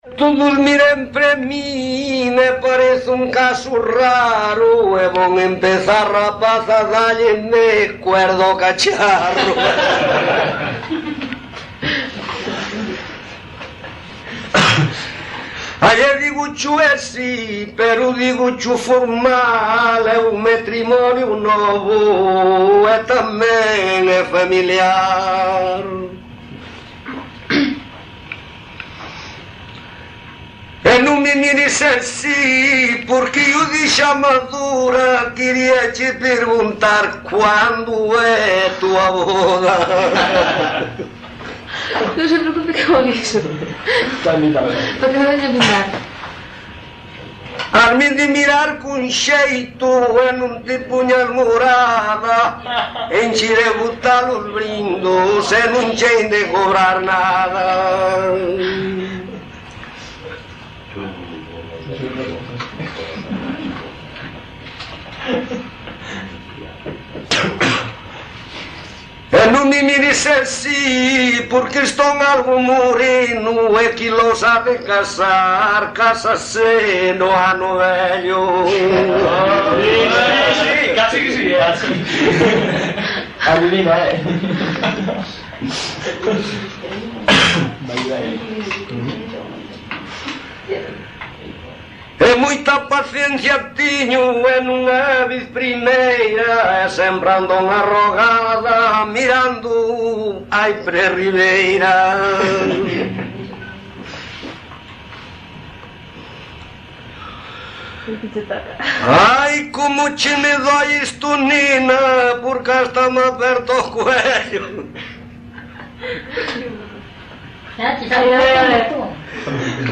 Tipo de rexistro: Musical
Áreas de coñecemento: LITERATURA E DITOS POPULARES > Coplas
Soporte orixinal: Casete
Xénero: Brindos
Instrumentación: Voz
Instrumentos: Voz masculina
Modo de interpretación: Parlando